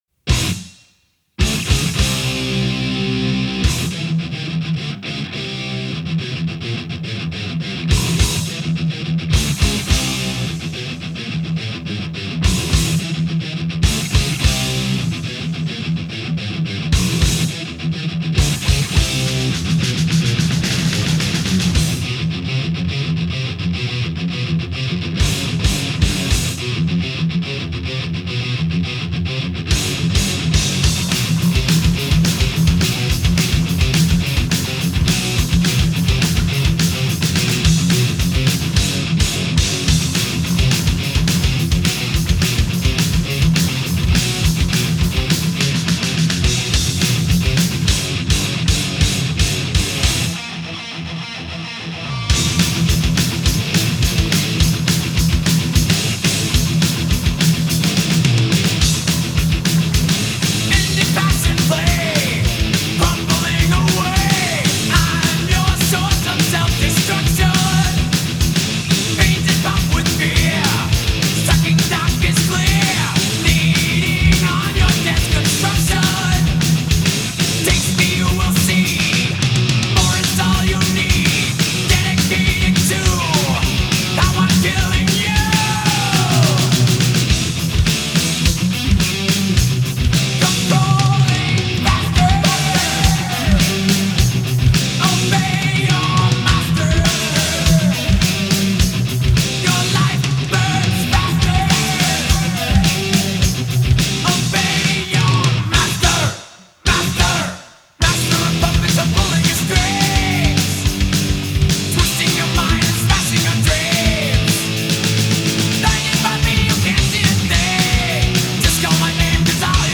Жанр: Thrash, Speed Metal, Heavy Metal